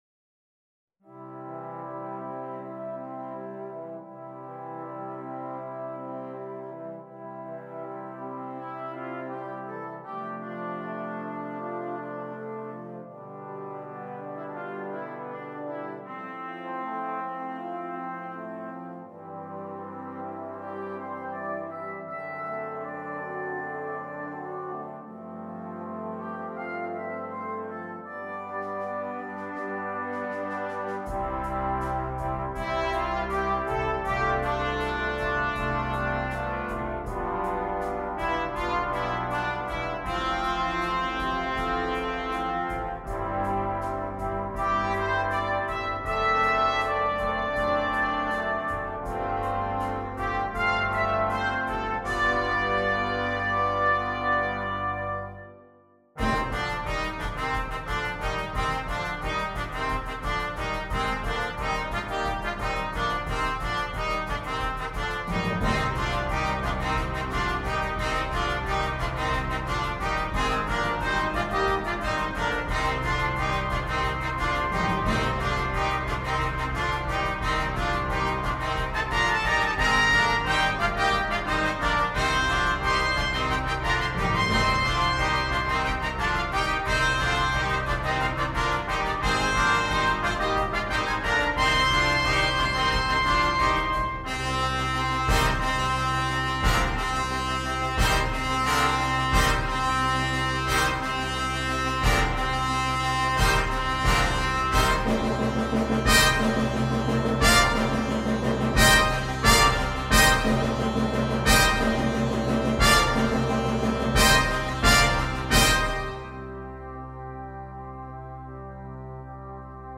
2. Blechbläserensemble
10 Blechbläser
ohne Soloinstrument
Unterhaltung
Stimme 5: Horn in F
Stimme 10: Tuba – Bass-Schlüssel
Perkussion